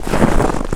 HauntedBloodlines/STEPS Snow, Walk 26-dithered.wav at main
STEPS Snow, Walk 26-dithered.wav